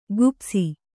♪ gupsi